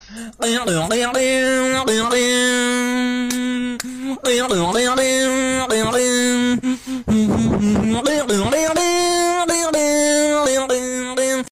Genre: Nada dering viral TikTok